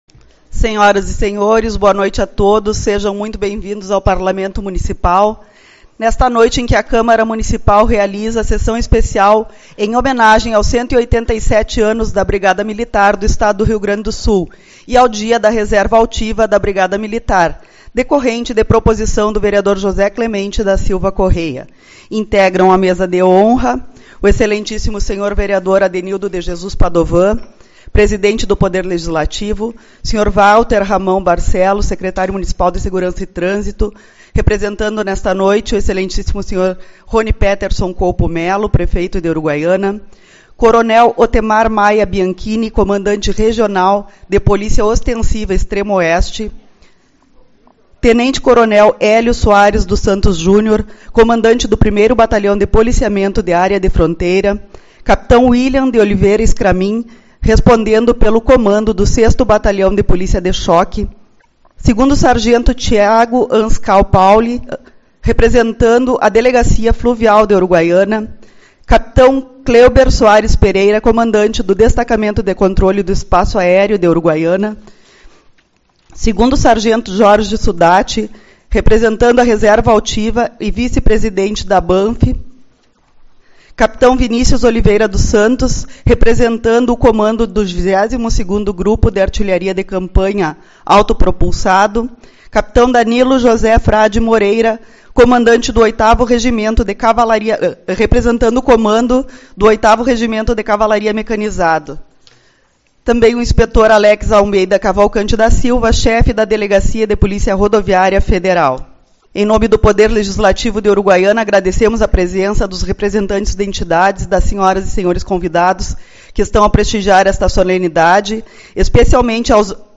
Sessão Especial-Brigada Militar e Reserva Altiva